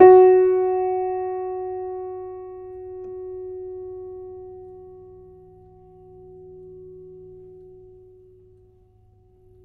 pianoFsh.ogg